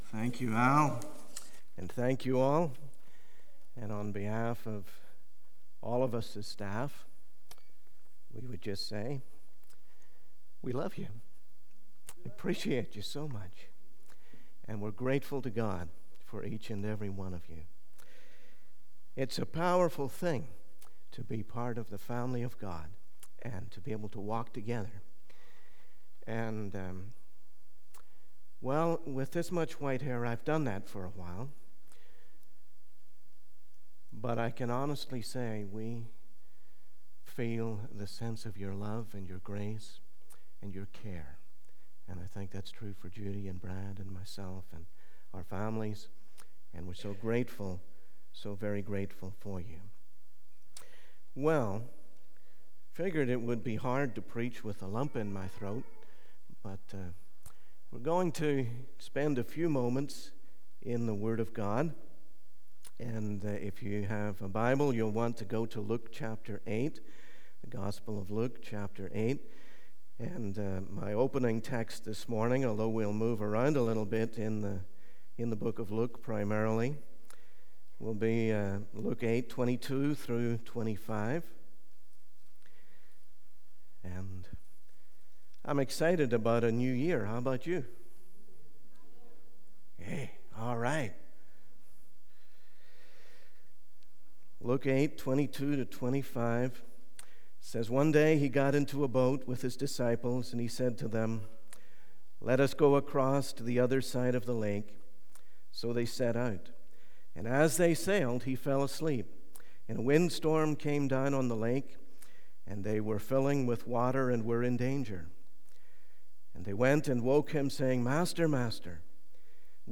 In this sermon, the preacher focuses on Luke chapter 10, specifically the first four verses.